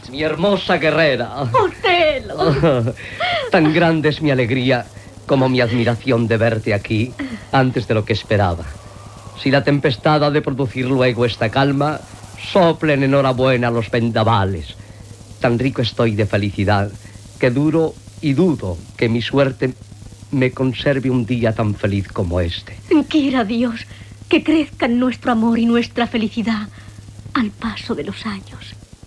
Fragment de l'adaptació radiofònica d '"Otel·lo: El moro de Venècia" de William Shakespeare.
Ficció